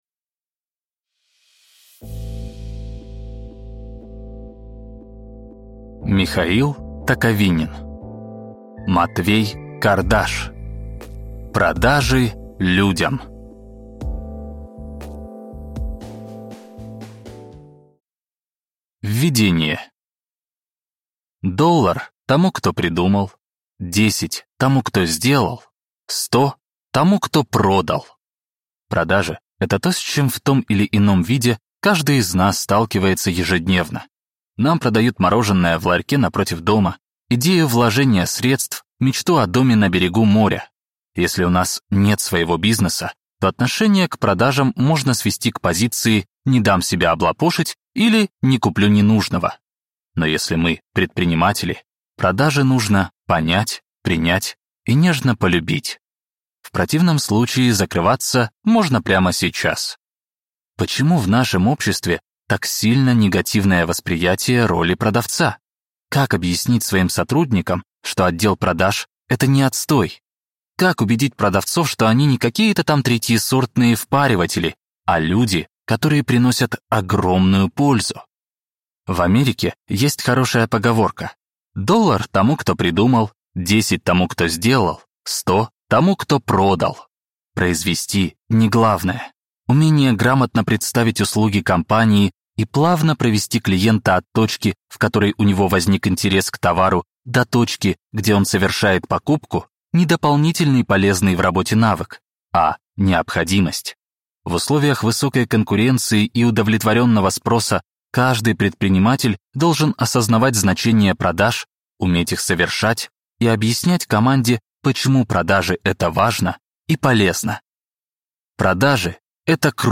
Аудиокнига Продажи людям: amoCRM от первого лица | Библиотека аудиокниг